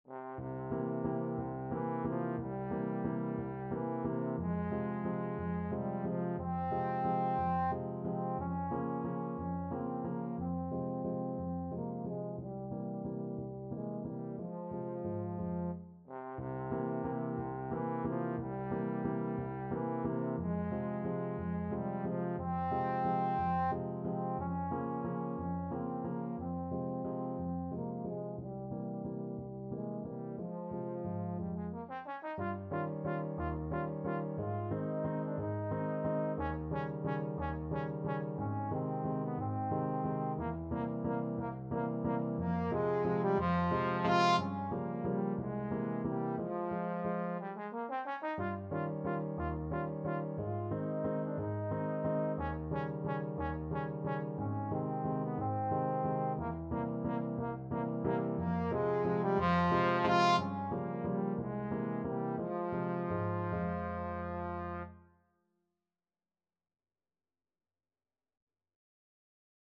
is a waltz
3/4 (View more 3/4 Music)
Waltz . = c.60
Classical (View more Classical Trombone Music)